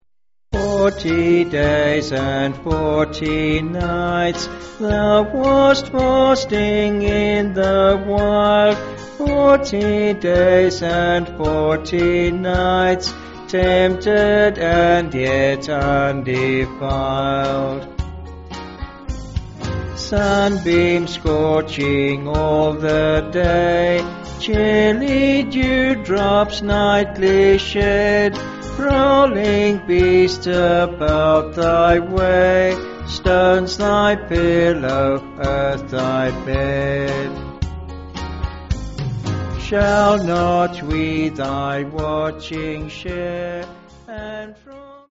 (BH)   6/Db-D
Vocals and Band